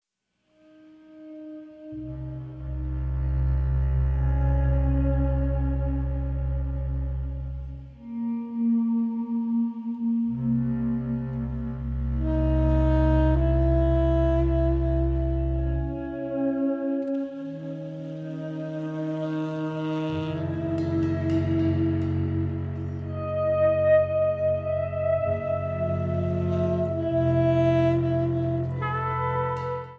• Movement 2 Improvisation: